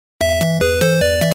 Videogame music and sound effects